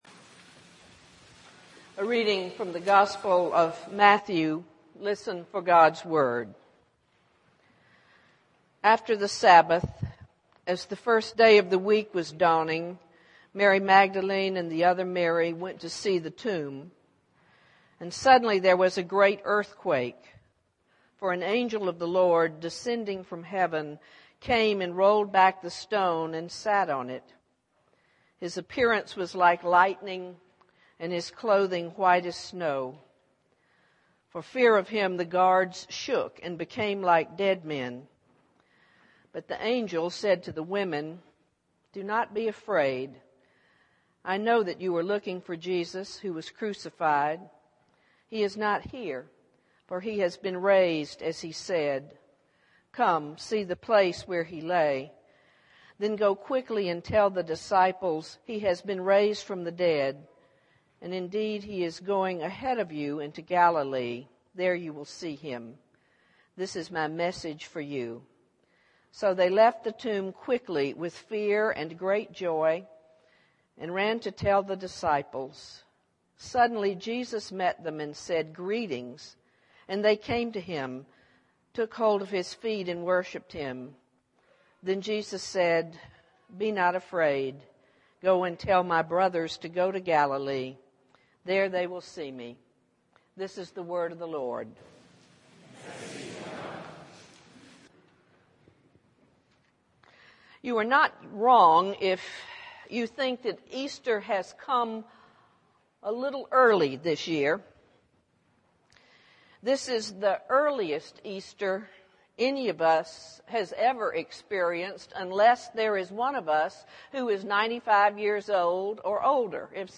worship_mar23_sermon.mp3